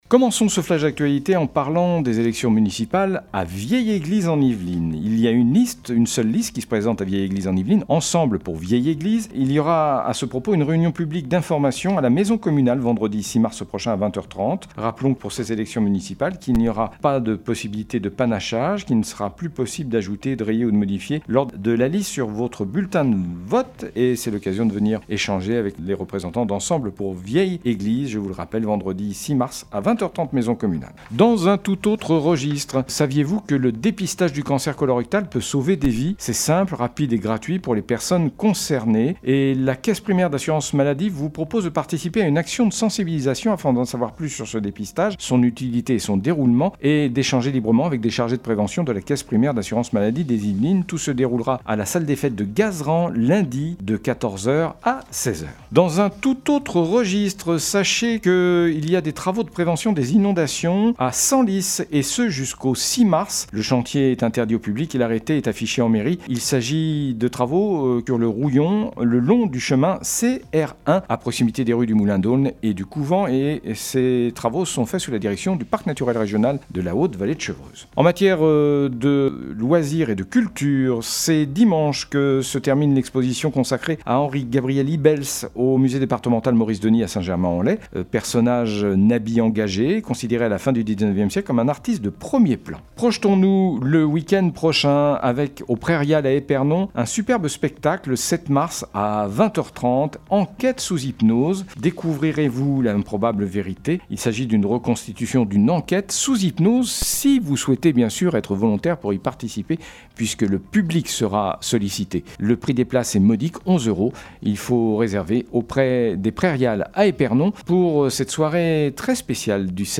L'information locale
28.02-flash-local-matin.mp3